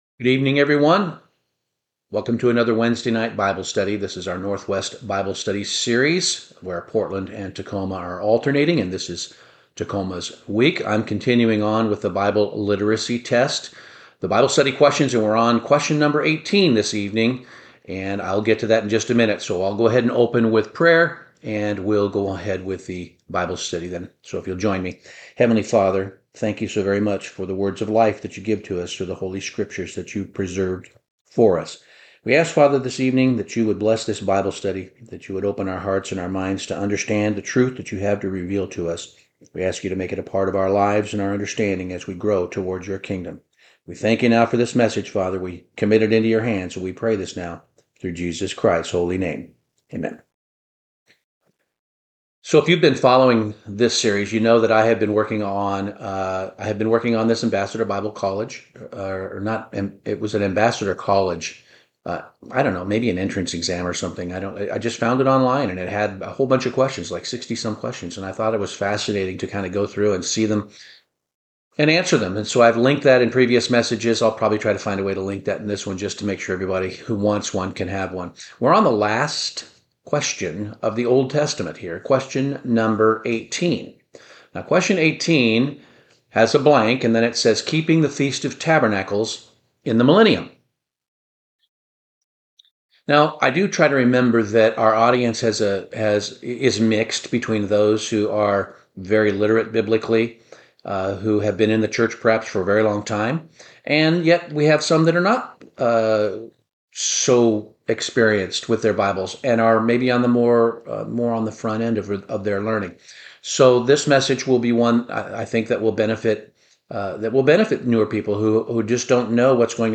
Continuing our series on the Bible Literacy Test, this bible study explores question #18 and why the feasts of the Lord will be kept during the millennium and beyond.